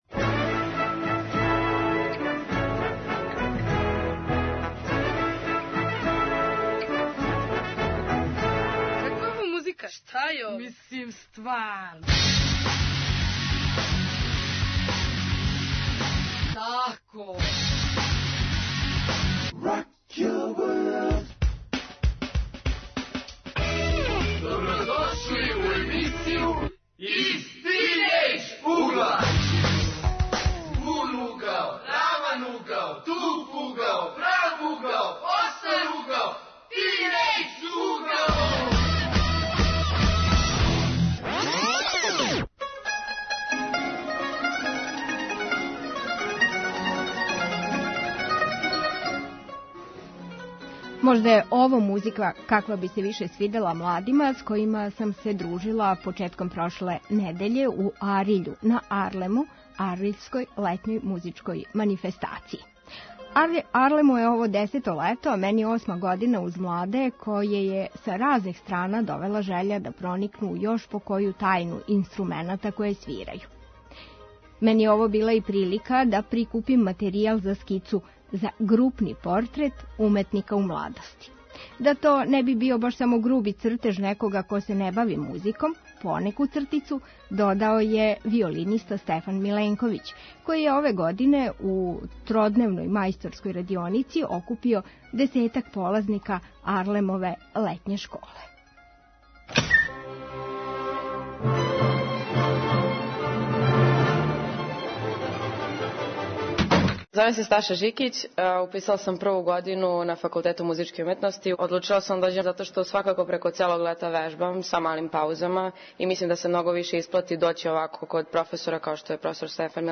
У јулу су сви разговори који се воде у Ариљу помало музички. Биће и овај у емисији Из тинејџ угла снимљеној на 10. АРЛЕММ-у, међу полазницима летње музичке школе.